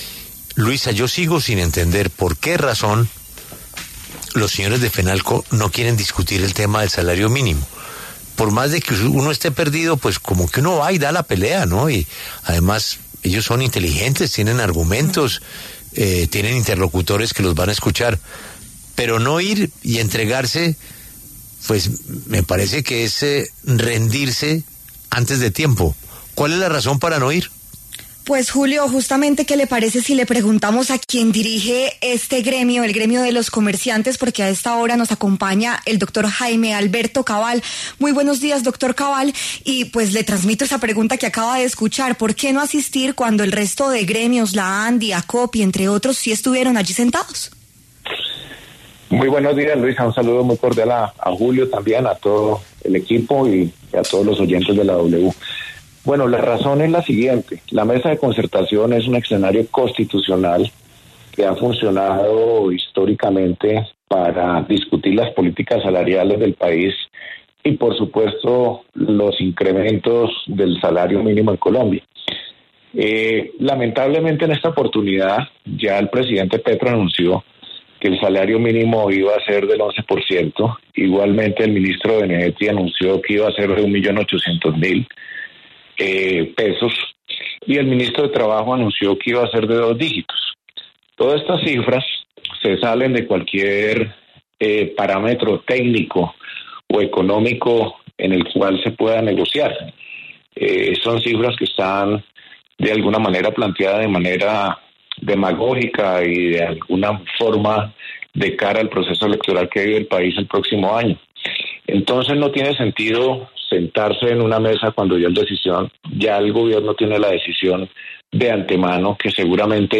hablaron en La W sobre el inicio de la mesa de negociación del salario mínimo de 2026.